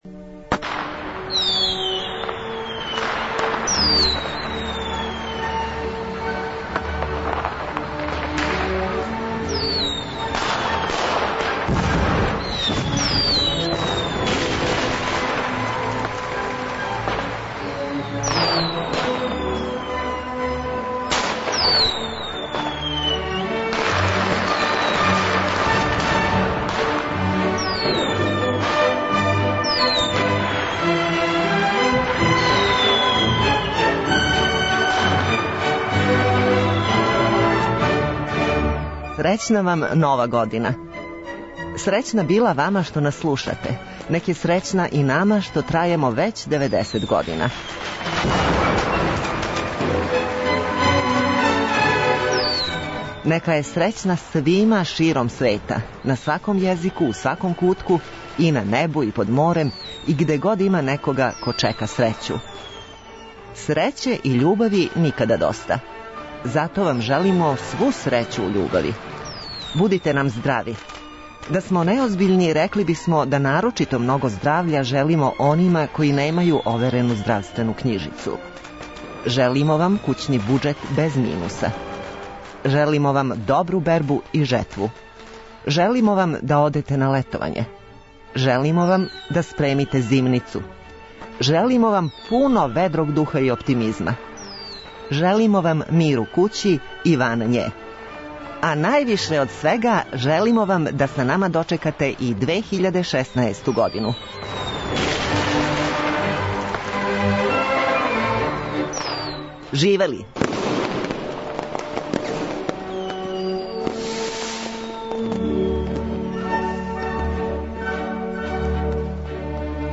У новогодишњем издању Ноћног програма бићемо у свету празничне музике. Слушаћемо одломке из оперета, бити у свету игара, слушати познате валцере и полонезе, а све то из опуса различитих композитора.